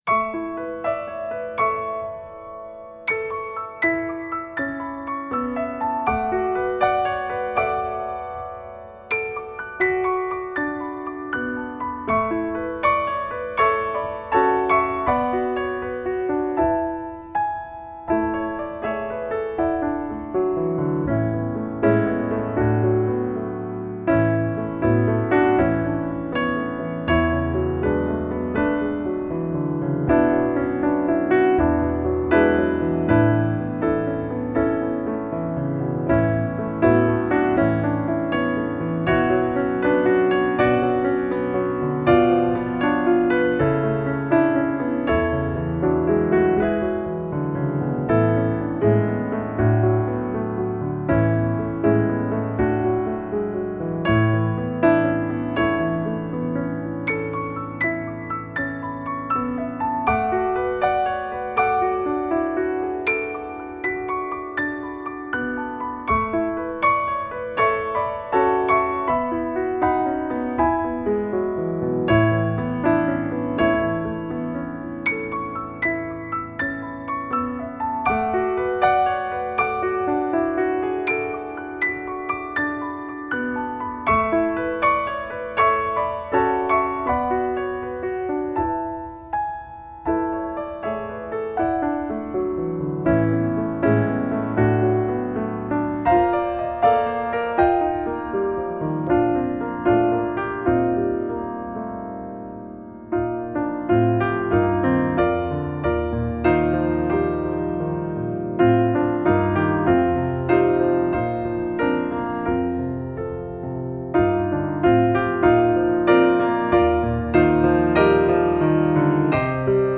Voicing: Piano